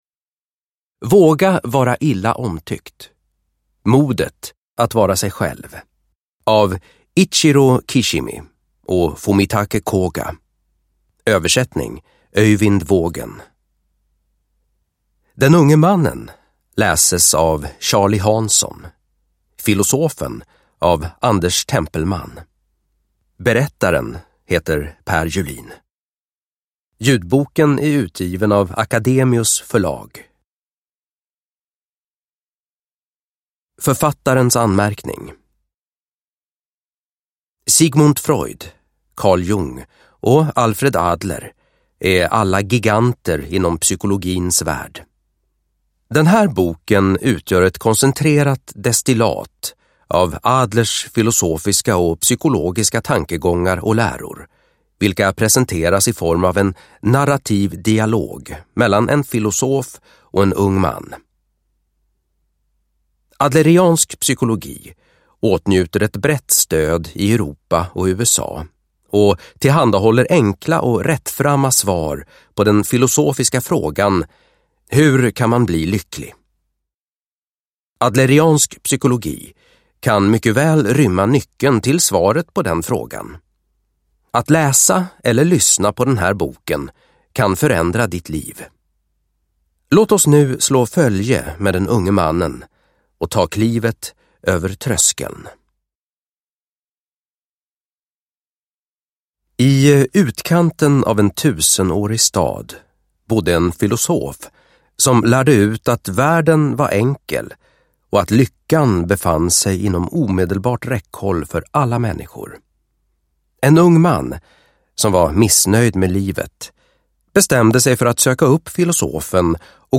Våga vara illa omtyckt: Modet att vara sig själv – Ljudbok